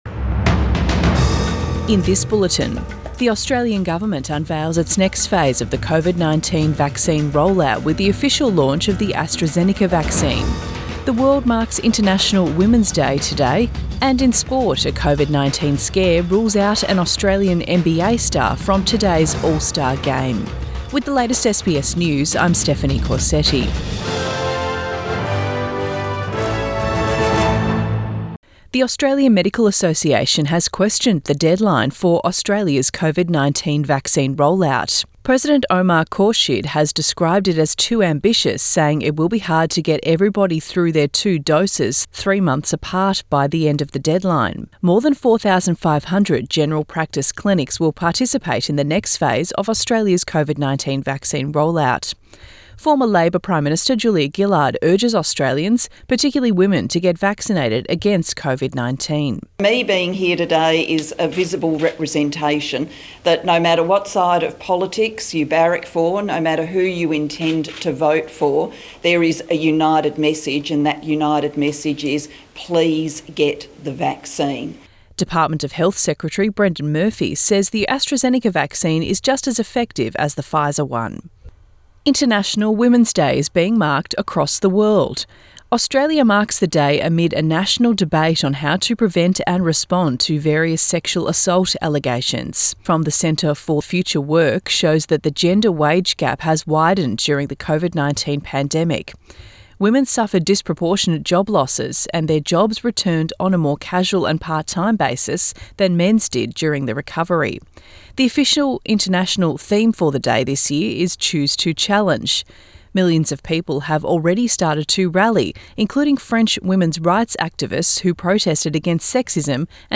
AM bulletin 8 March 2021